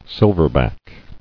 [sil·ver·back]